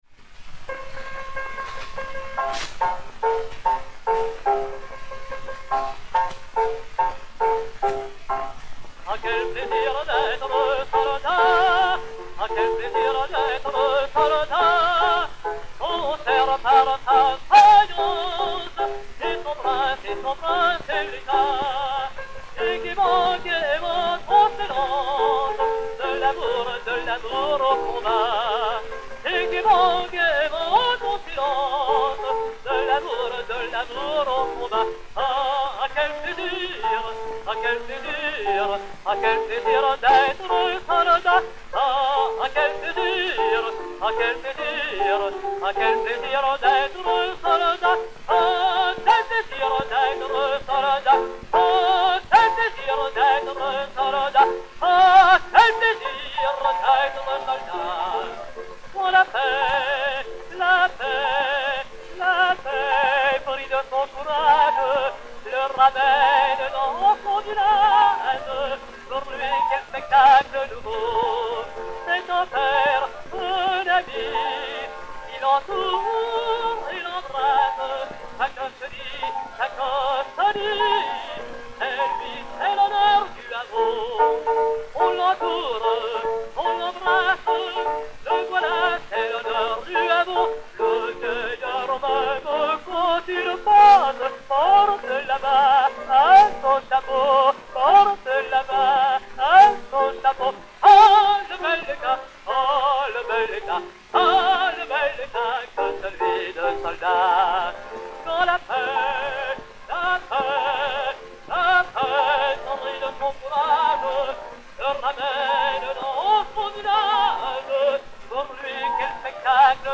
Edmond Clément (Georges Brown) [avec dédicace] et Orchestre
XPh 758, enr. à Paris vers 1905